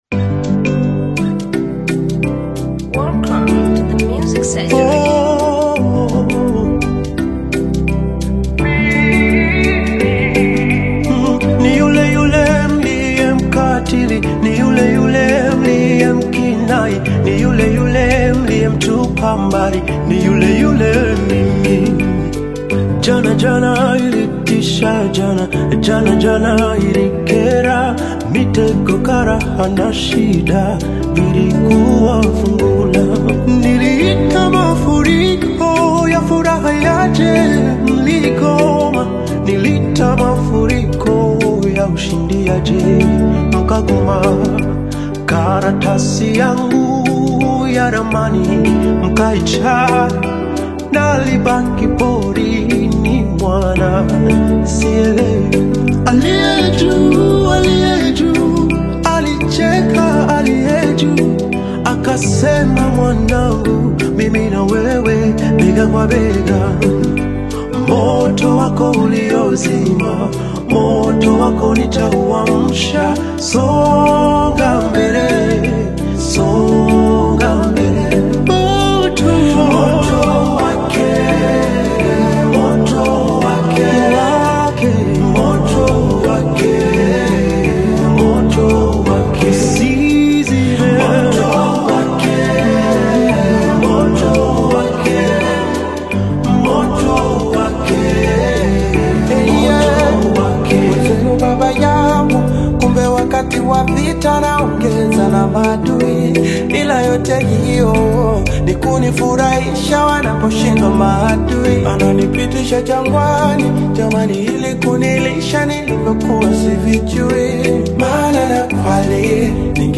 AudioGospel
Tanzanian gospel single
heartfelt vocal delivery